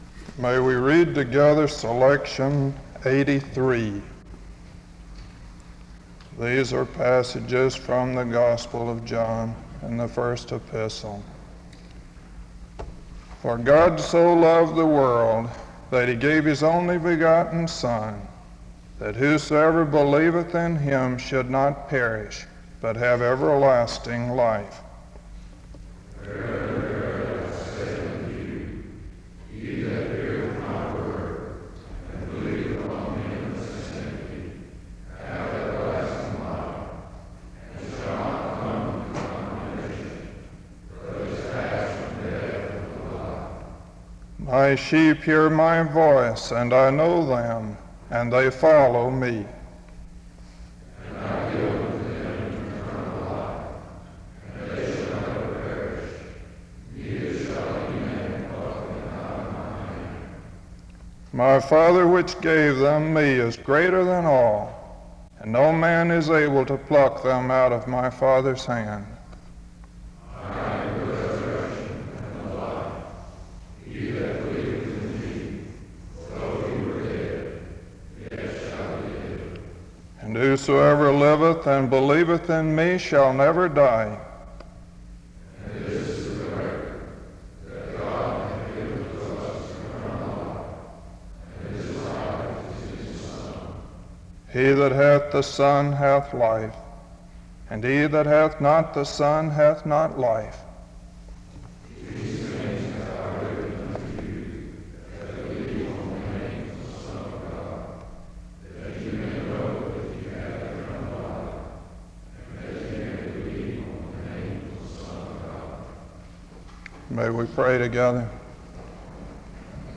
The service begins with a responsive reading from 0:00-1:56. A prayer is offered from 1:56-6:12.